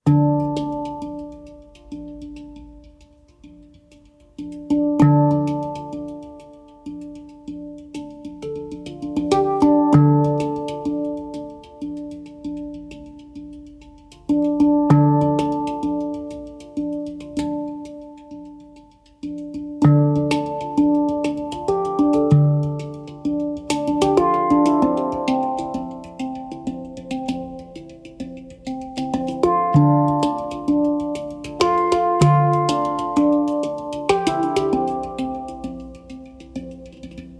دانلود آهنگ هنگ درام برای مدیتیشن و آرامش